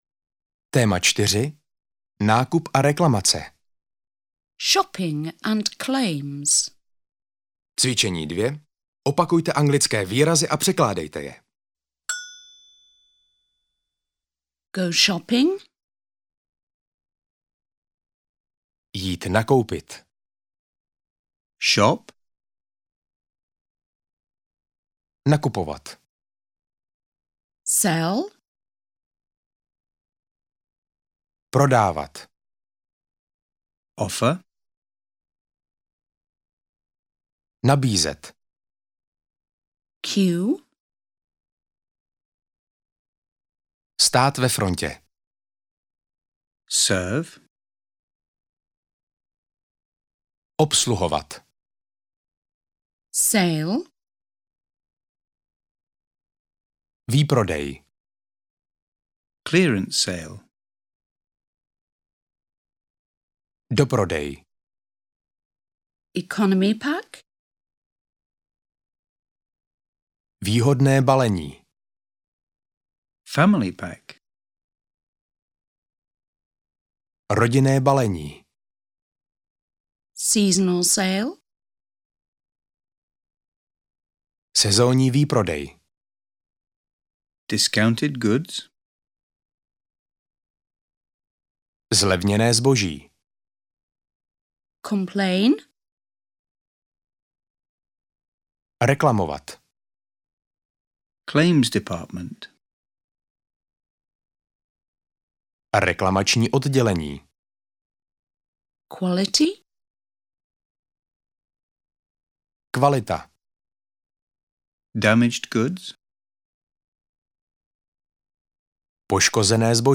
Audiokniha Anglicky bez učebnice - Nakupování, kterou napsaly Anna Kronusová a Markéta Galatová, obsahuje nahrávky v anglickém jazyce s českým komentářem, různorodá témata a cvičení včetně opakování slovíček a správné výslovnosti.
Ukázka z knihy